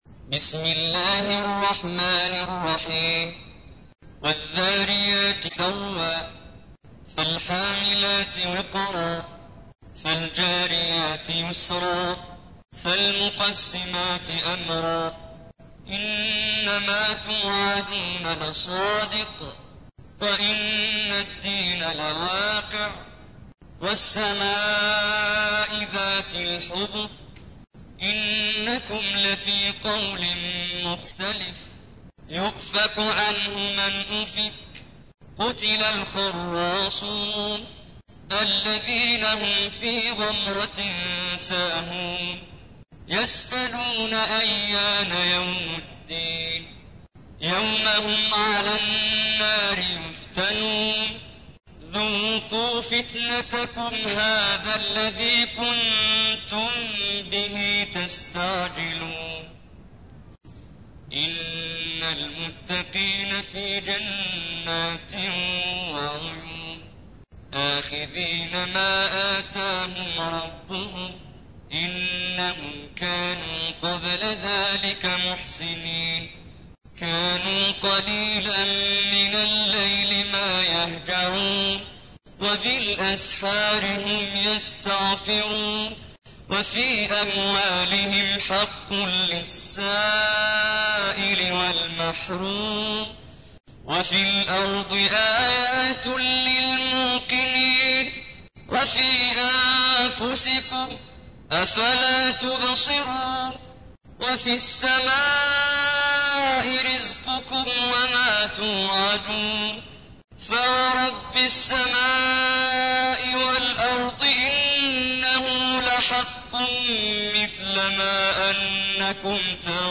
تحميل : 51. سورة الذاريات / القارئ محمد جبريل / القرآن الكريم / موقع يا حسين